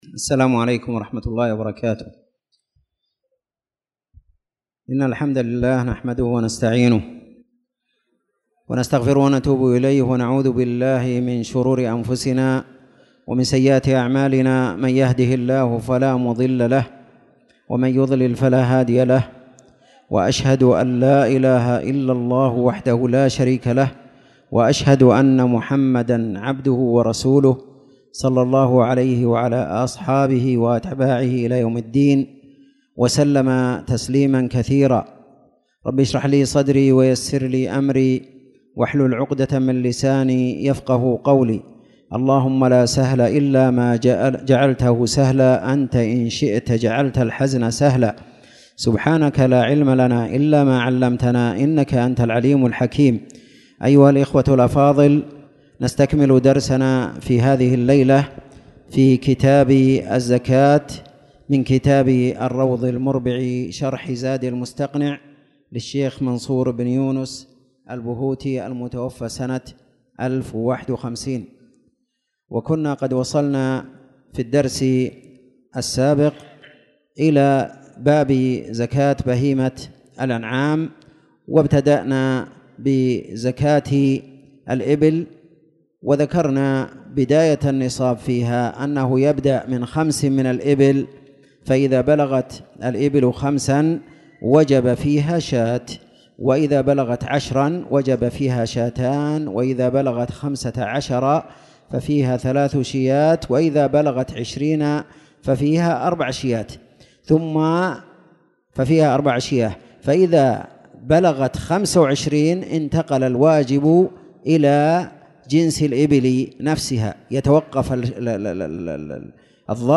تاريخ النشر ٩ شعبان ١٤٣٧ هـ المكان: المسجد الحرام الشيخ